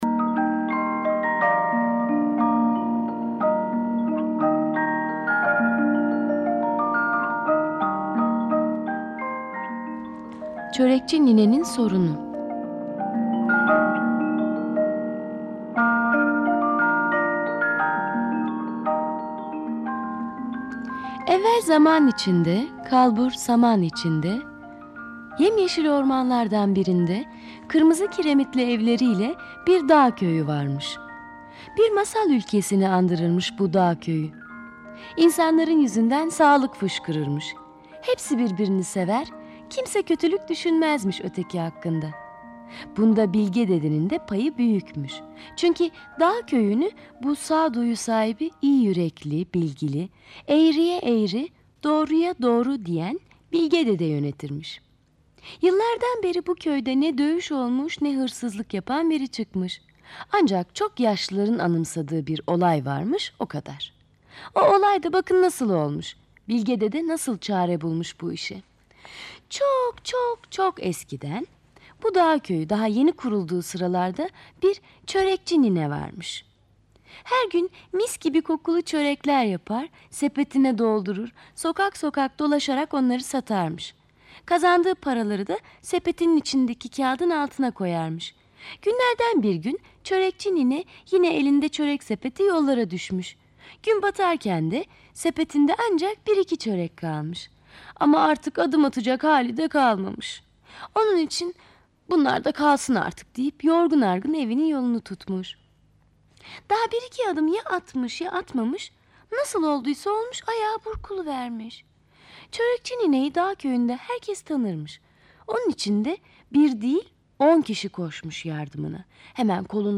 Kategori Sesli Çocuk Masalları